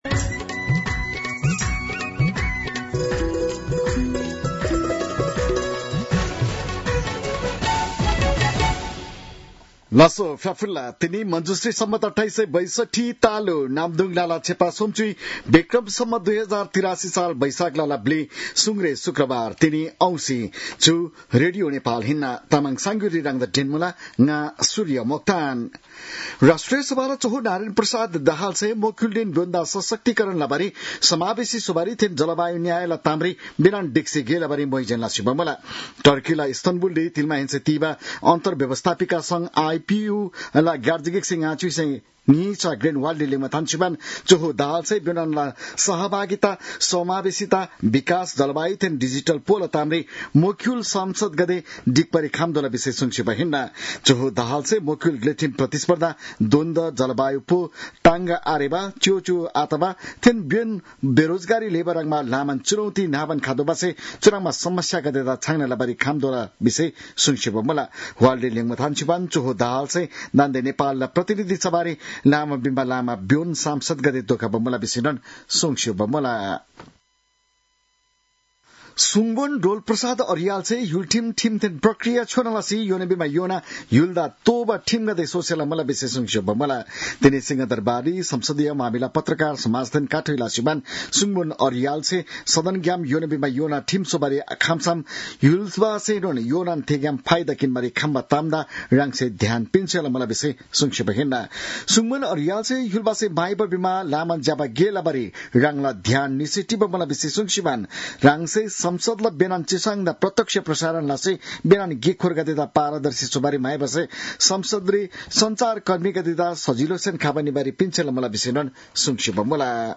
तामाङ भाषाको समाचार : ४ वैशाख , २०८३